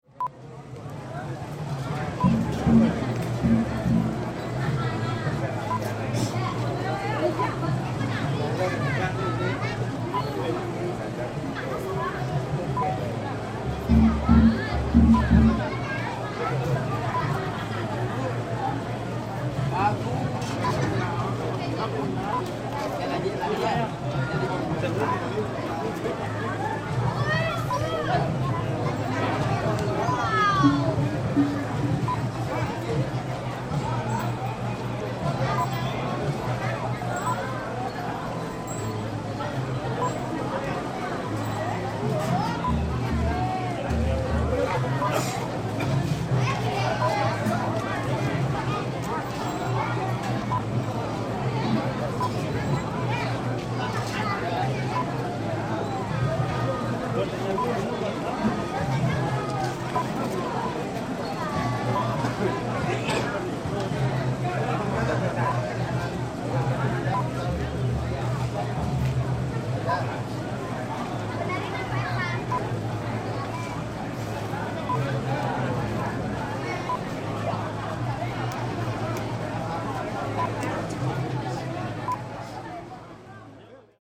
Bali Oge Oge Street Parade, Carnival, Festival 5
Bali Oge oge new year street parade, recorded in Kuta the night before Nyepi. Lots of crowd hubbub, chatter, laughter, coughing. sneezing Mainly Indonesian voices there are also Australian and English voices. People walking by. Children scream and talk. Some drums rolls and hits, kadjar hits. 16 bit 48kHz Stereo WAV
IndonesianStreetParade5_plip.mp3